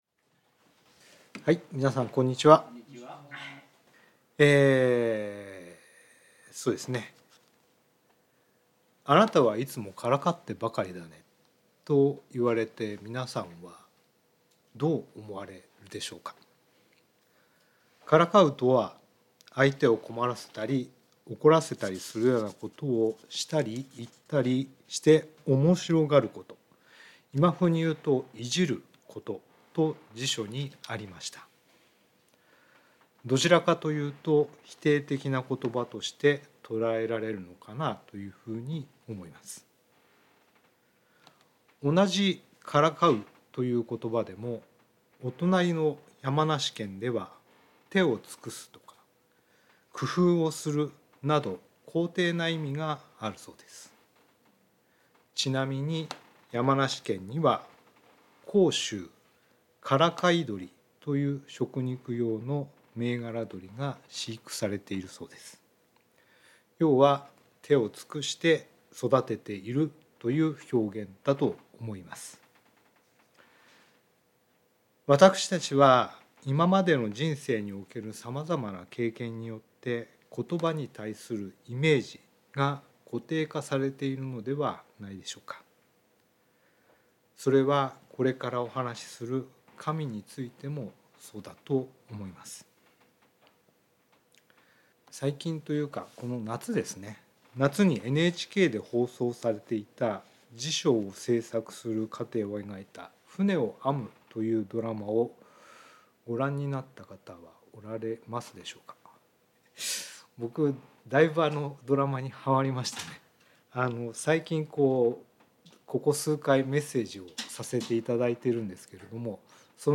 聖書メッセージ No.294